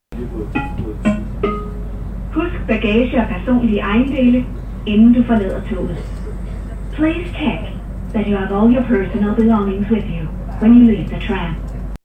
Højttalerudkald Metro og Letbane